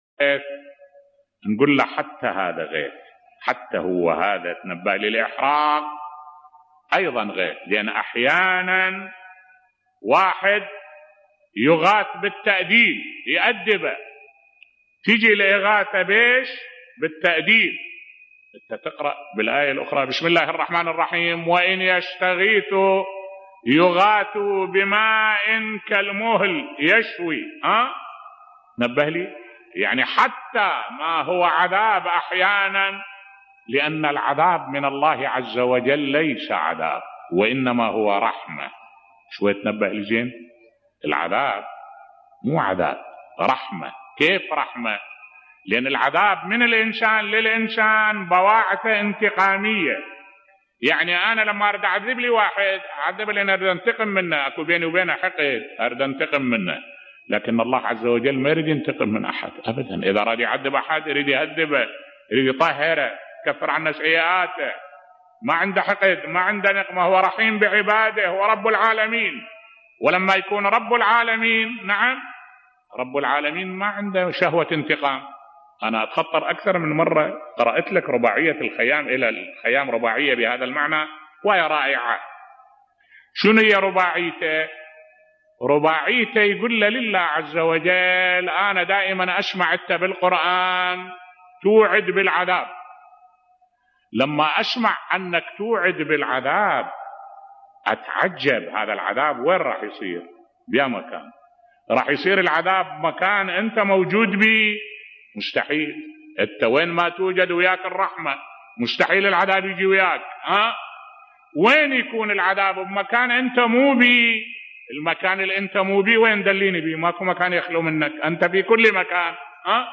ملف صوتی عذاب الله رحمة وتأديب للبشر بصوت الشيخ الدكتور أحمد الوائلي